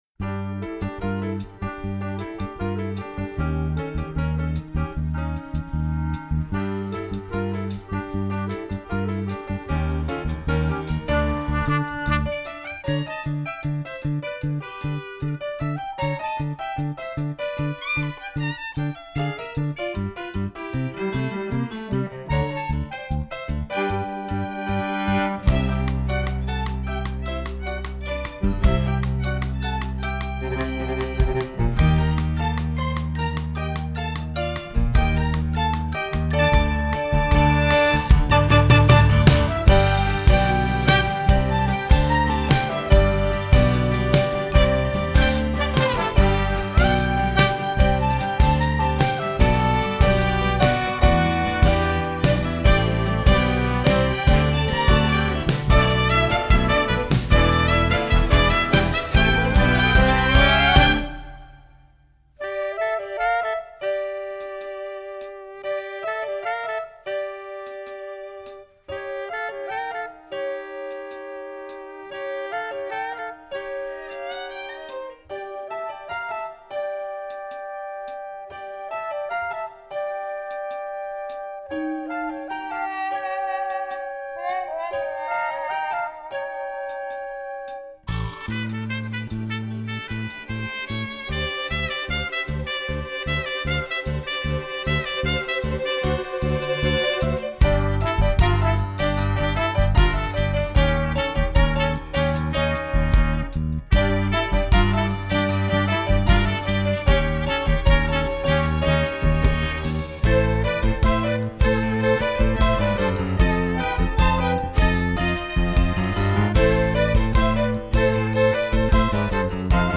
Performance Accompaniment Tracks: